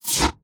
Select Scifi Tab 1.wav